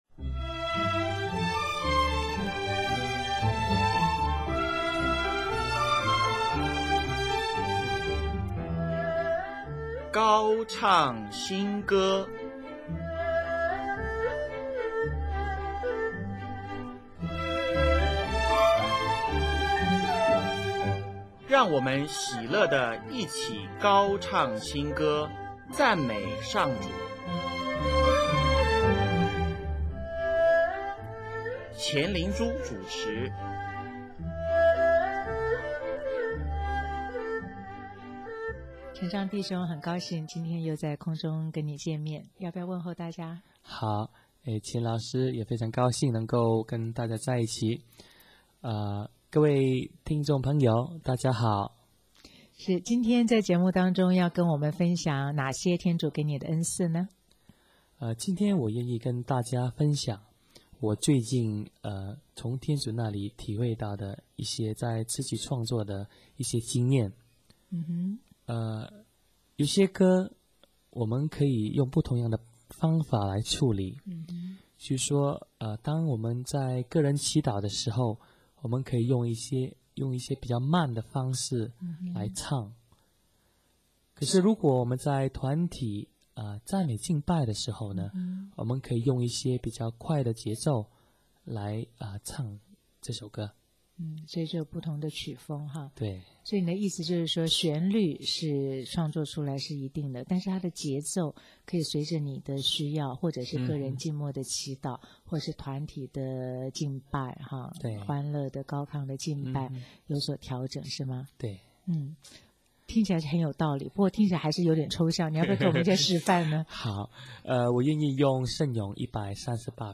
随著节奏加快，演唱者和听者都忍不住手舞足蹈，在歌声中，整个人由内到外全部投入。